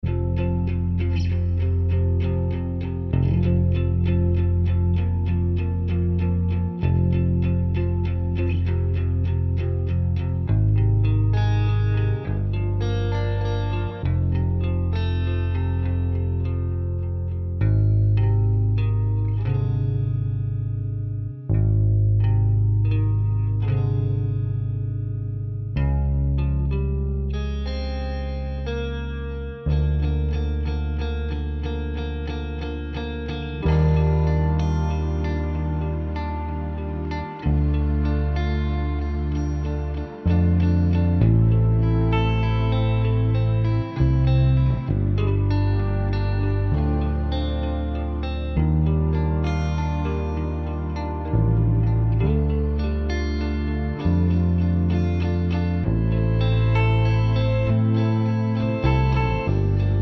Indie / Alternative